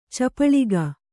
♪ capaḷiga